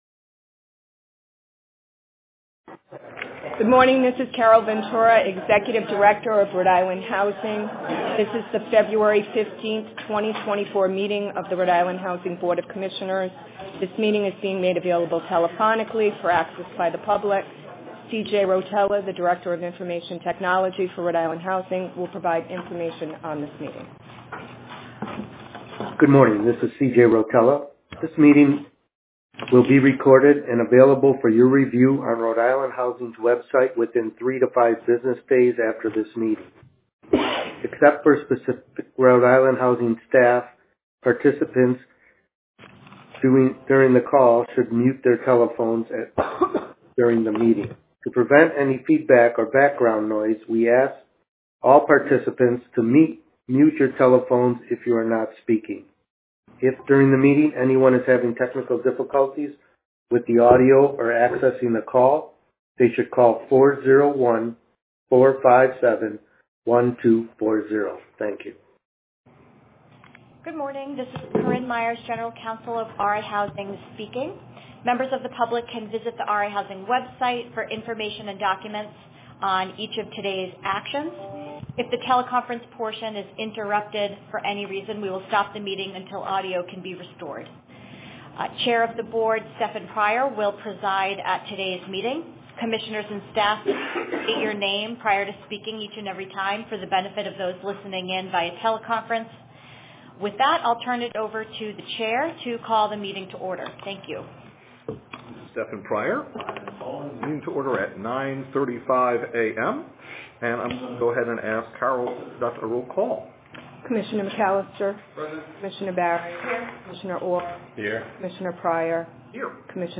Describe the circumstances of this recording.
Recording of RIHousing Board of Commissioners Meeting: 02.15.2024